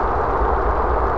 concrete_roll.wav